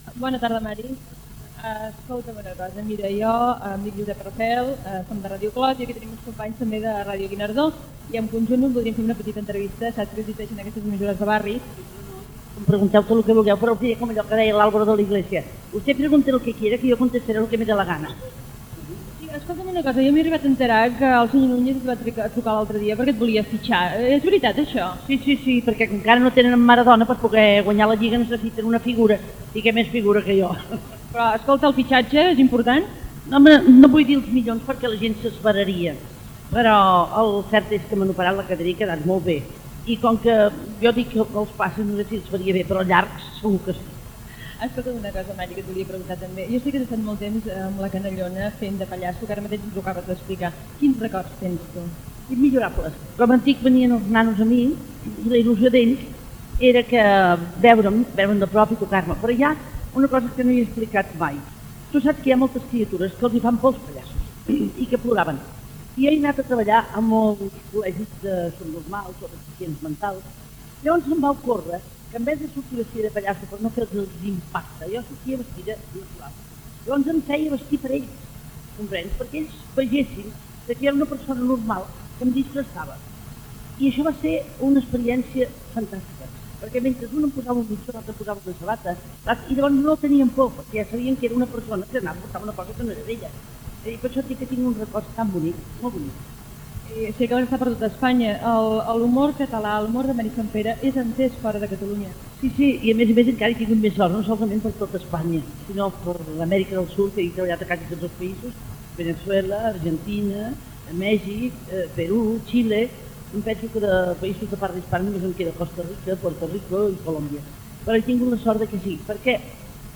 entrevista al carrer a la vedet i humorista catalana Mary Santpere. També hi ha els micròfons de Ràdio Guinardó.
Entreteniment